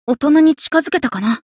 觉醒语音 大人に近づけたかな 媒体文件:missionchara_voice_484.mp3